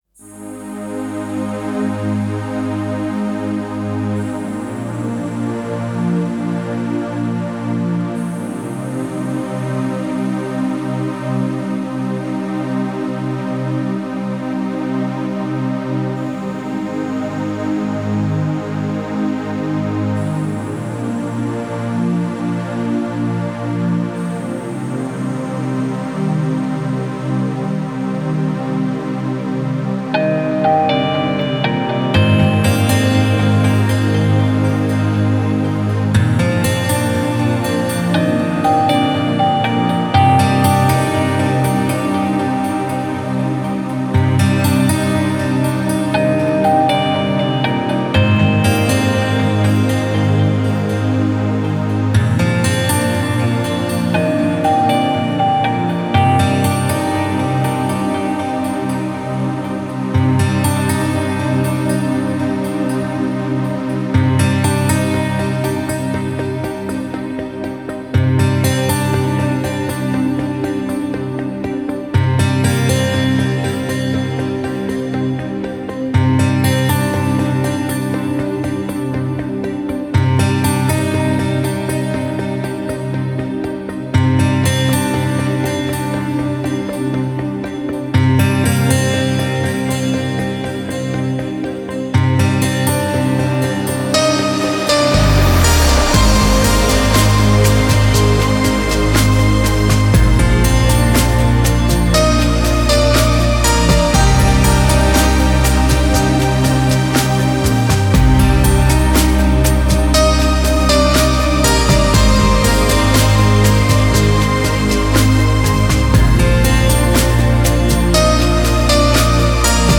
Спокойная музыка
Релаксирующая музыка